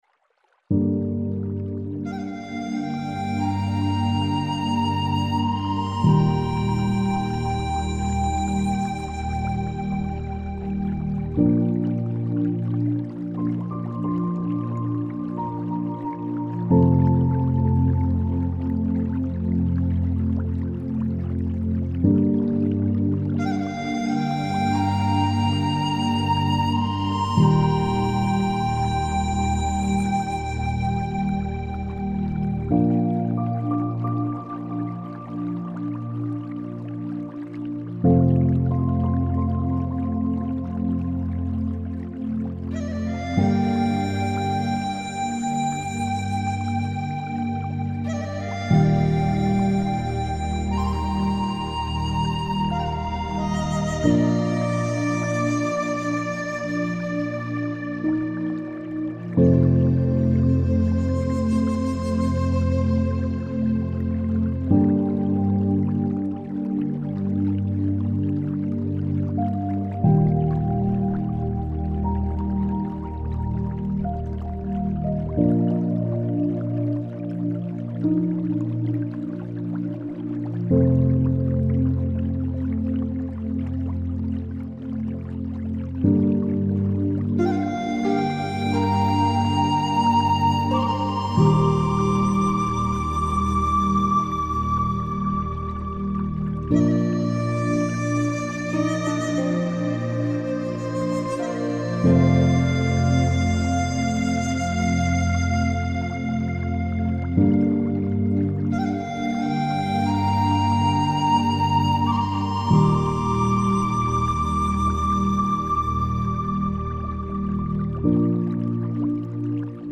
موسیقی بی کلام , آرامش بخش , پیانو , مدیتیشن